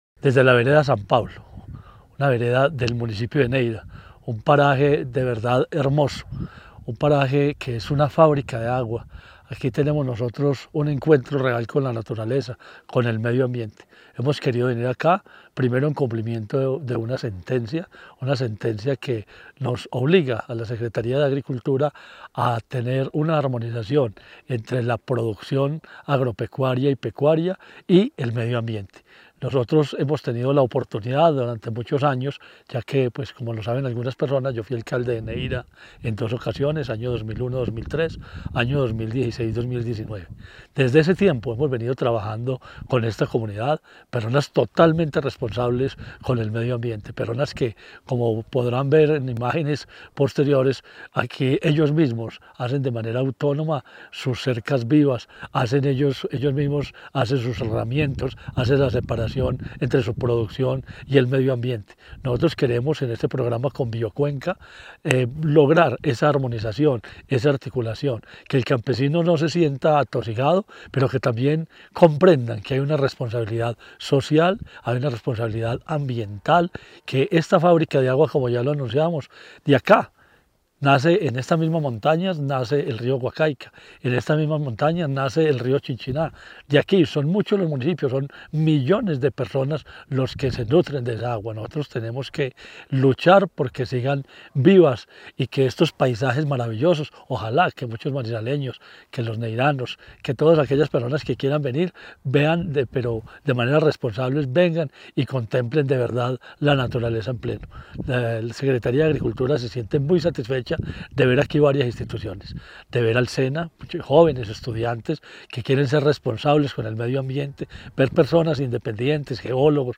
La jornada se desarrolló en la finca El Mirador, ubicada en la vereda San Pablo del municipio de Neira, permitiendo a los cerca de 40 asistentes conocer de primera mano prácticas de ganadería sostenible y cultivos de papa amigables con el medio ambiente.
Marino Murillo Franco, secretario de Agricultura y Desarrollo Rural de Caldas